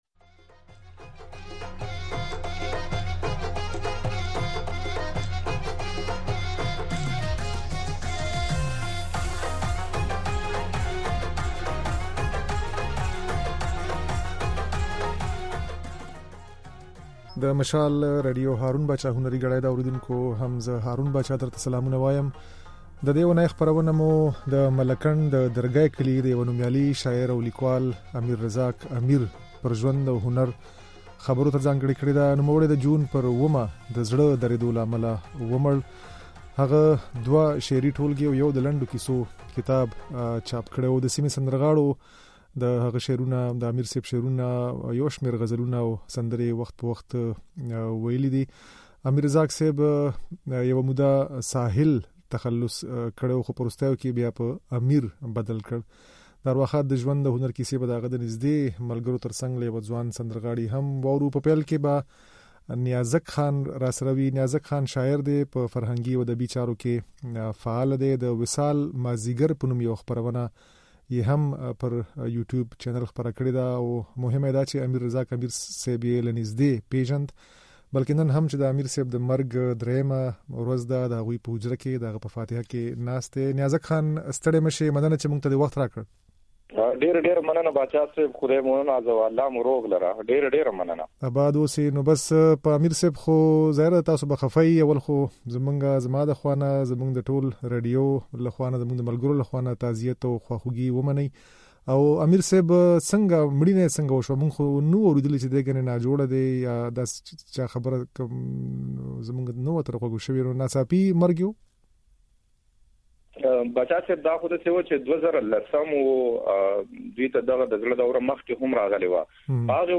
د سيمې شاعرانو او فرهنګيانو د امير مړينه د پښتني شعر و ادب لپاره لويه ضايعه بللې. په خپرونه کې د دوی د خبرو ترڅنګ له موسيقۍ سره ويل شوي د امير رزاق امير ځينې شعرونه هم اورېدای شئ.